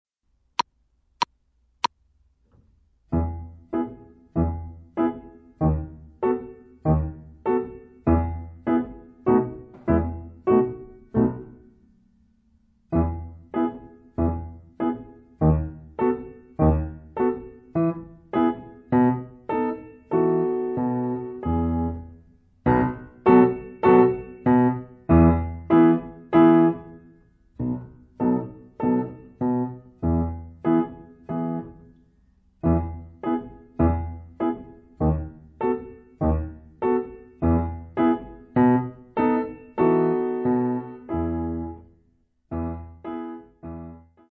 Flöte und Klavier